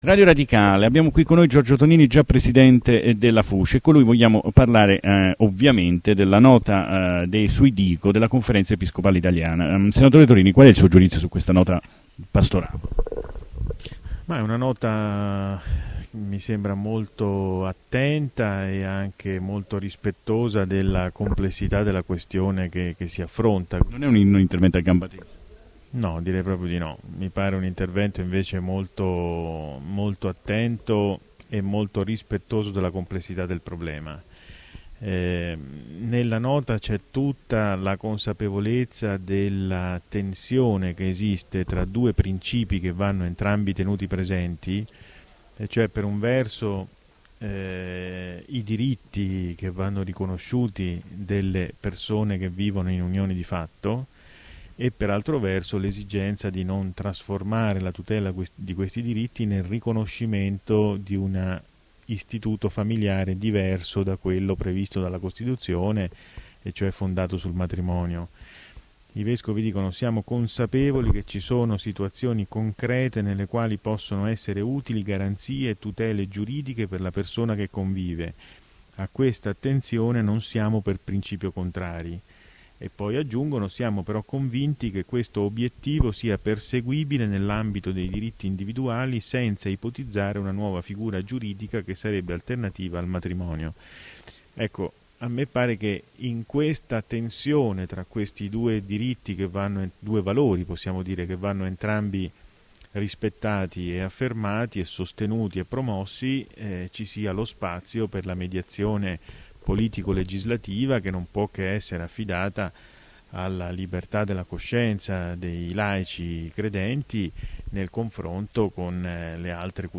DiCo: intervista a Radio Radicale